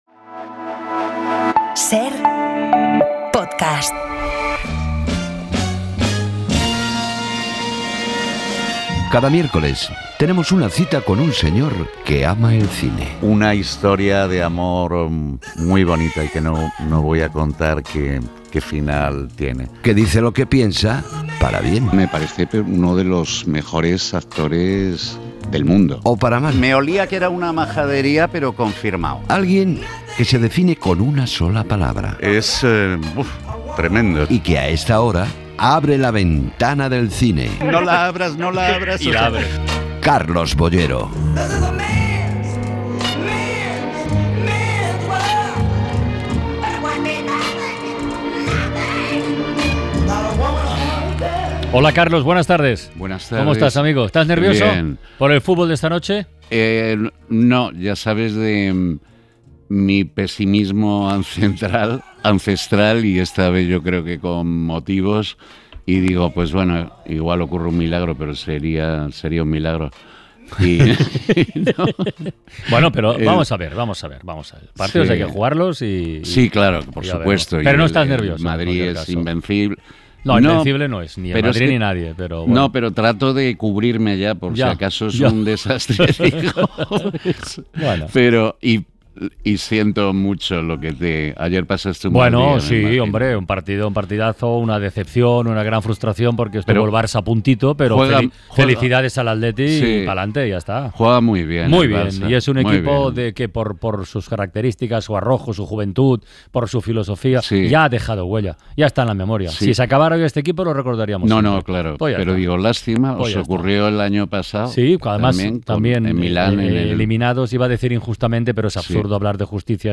Nuestro crítico de cine Carlos Boyero visita 'La Ventana' para hablarnos de los nuevos estrenos que llegan a la cartelera esta semana.